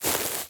sfx_walk_grass.mp3